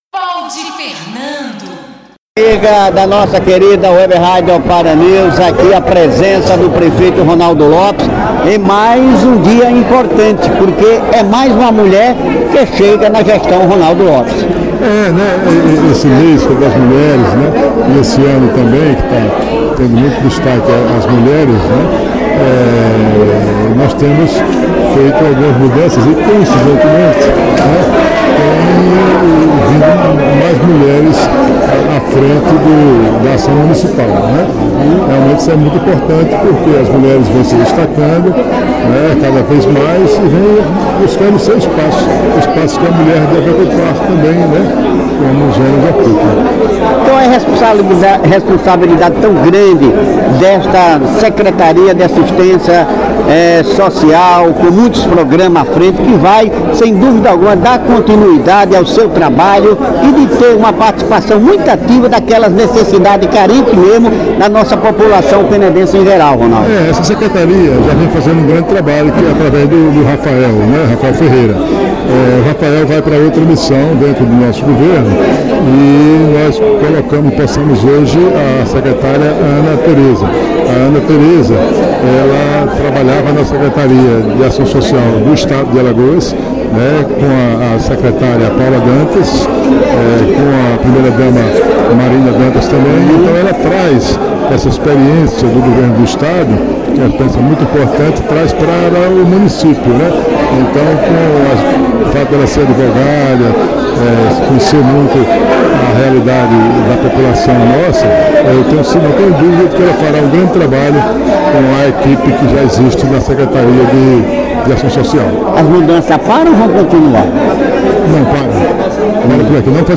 O portal de notícias, OparaNews, esteve presente na posse da advogada Ana Teresa Koenigkan Vieira Machado Lopes na Secretaria Municipal de Desenvolvimento Social e Direitos Humanos (SEMASDH).
Na oportunidade foram entrevista a secretária Ana Teresa e o prefeito de Penedo Ronaldo Lopes.